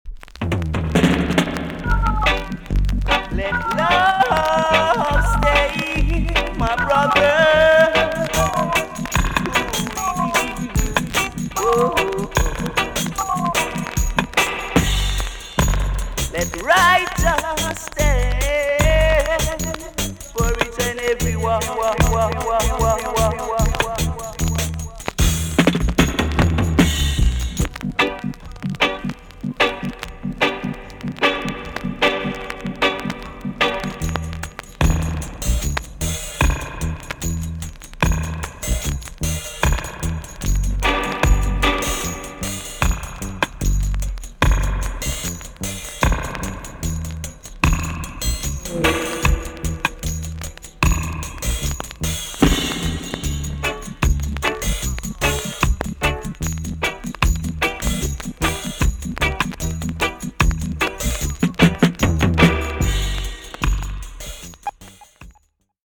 TOP >REGGAE & ROOTS
B.SIDE Version
VG ok 軽いチリノイズが入ります。